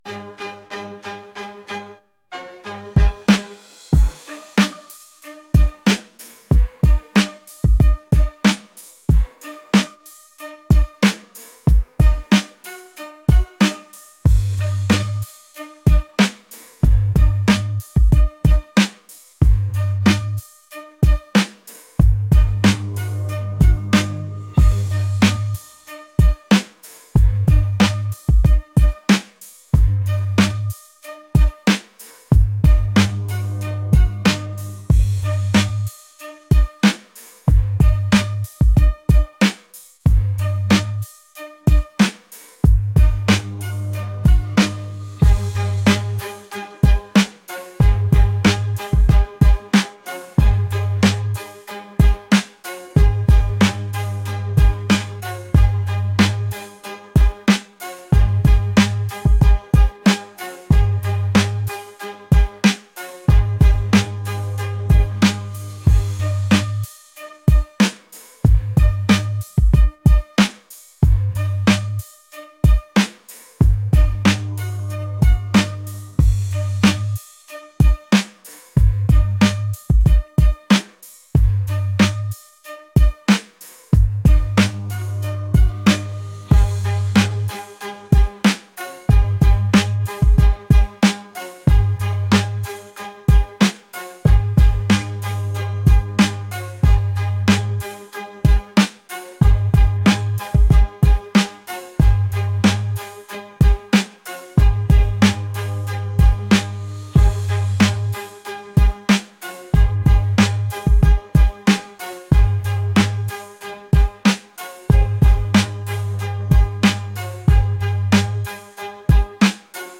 energetic | upbeat